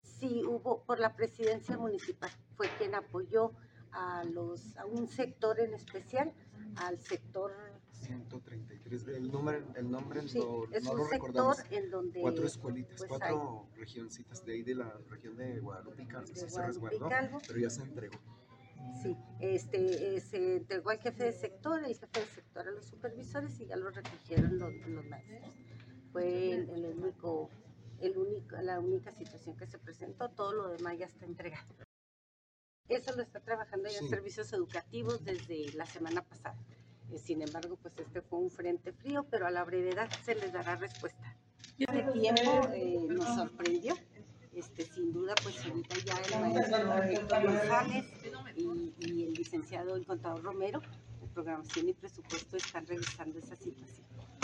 AUDIO: SANDRA GUTIÉRREZ, SECRETARÍA DE EDUCACIÓN Y DEPORTE (SEyD)